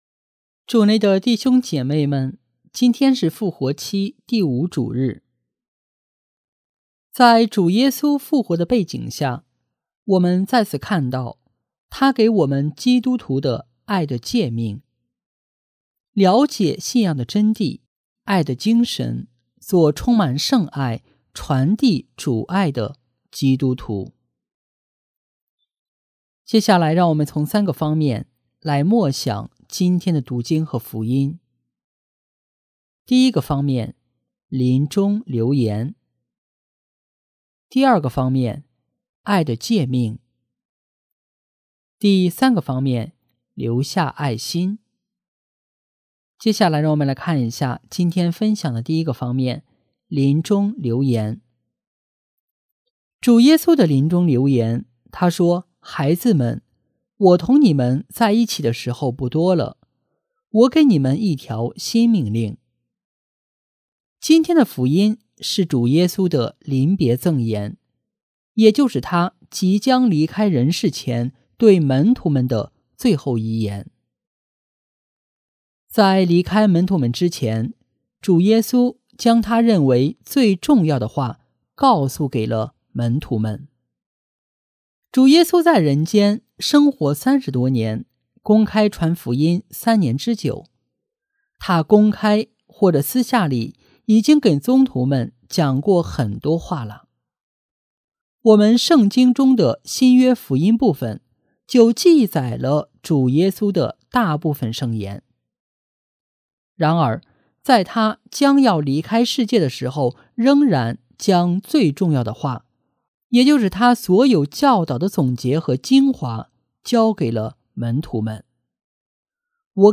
【主日证道】| 爱的留言（丙-复活期第5主日）